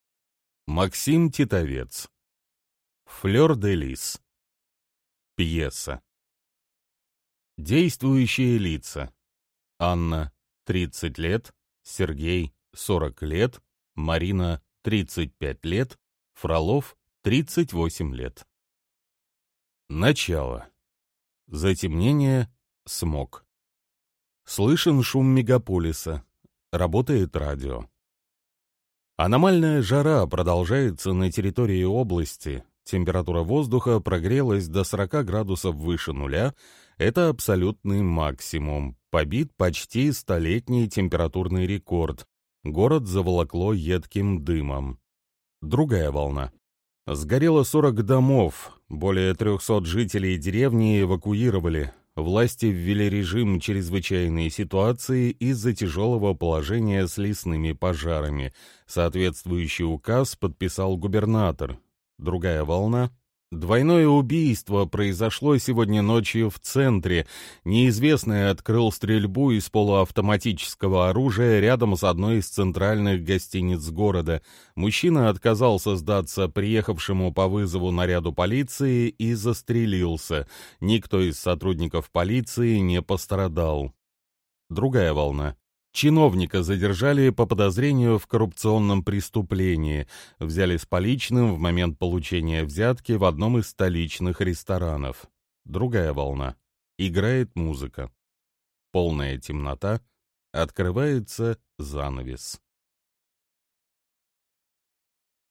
Аудиокнига Флёр-де-Лис. Пьеса | Библиотека аудиокниг
Прослушать и бесплатно скачать фрагмент аудиокниги